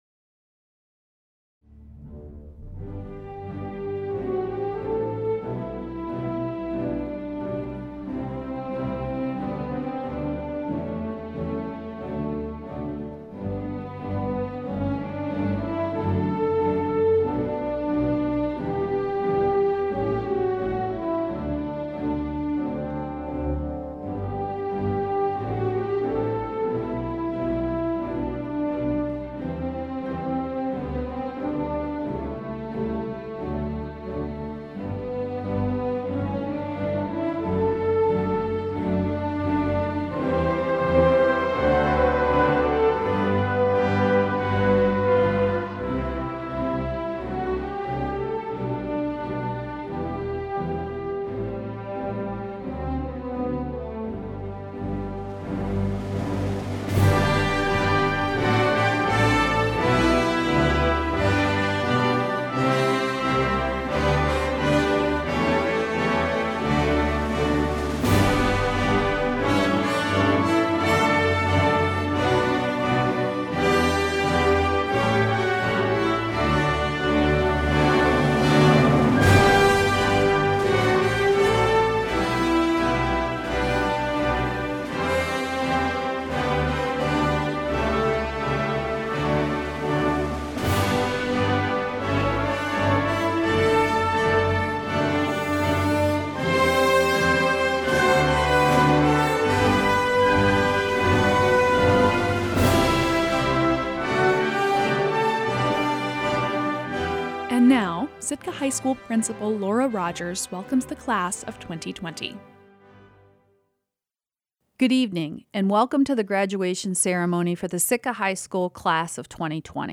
72 Sitka High School seniors graduated in the school’s first ever drive-thru ceremony on Monday (5-18-20). Students parked their cars in the Sitka High School parking lot, heard speeches from fellow graduates and honored faculty, and then drove through, one-by-one, to pick up their diplomas.